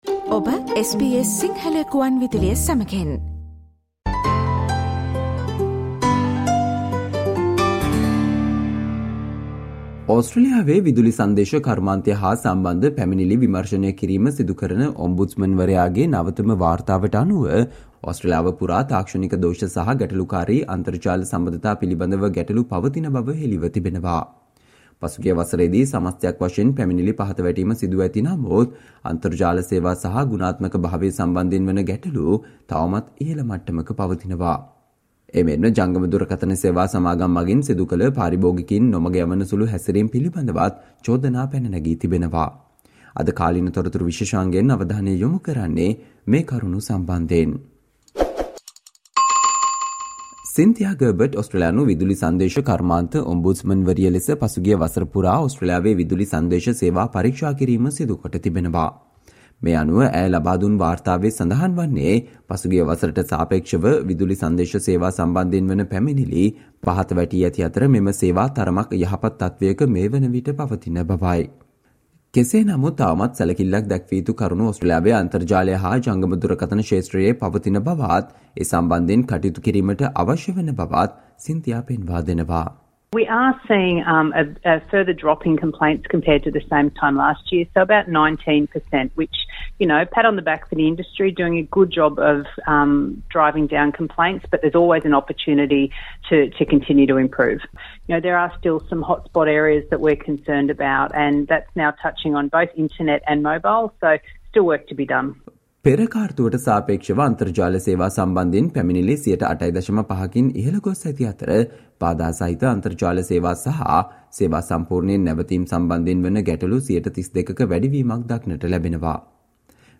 Today - 15 May, SBS Sinhala Radio current Affair Feature on Allegations on problems with internet and mobile phone service companies in Australia